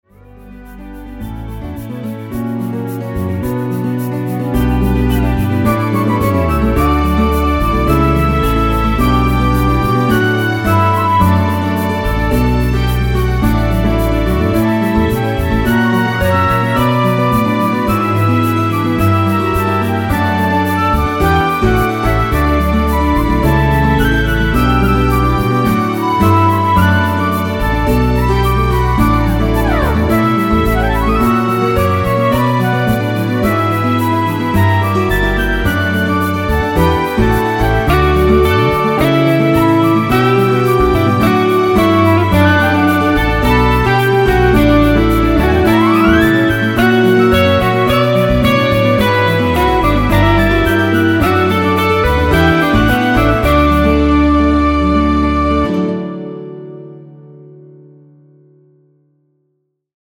엔딩부분 미리듣기
엔딩이 페이드 아웃이라 라이브 하시기 좋게 엔딩을 만들어놓았습니다.
원키 멜로디 포함된 MR입니다.